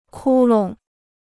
窟窿 (kū long): hole; pocket.